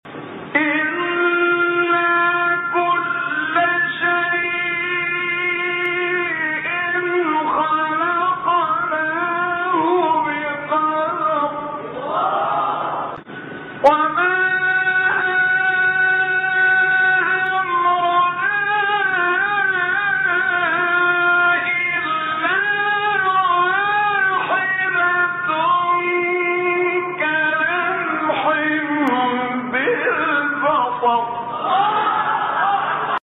سوره : قمر آیه: 50-49 استاد : عبدالفتاح شعشاعی مقام : حجاز روایت : ورش از نافع مدنی إِنَّا كُلَّ شَيْءٍ خَلَقْنَاهُ بِقَدَرٍ ﴿٤٩﴾ وَمَا أَمْرُنَا إِلَّا وَاحِدَةٌ كَلَمْحٍ بِالْبَصَرِ ﴿٥٠﴾ قبلی بعدی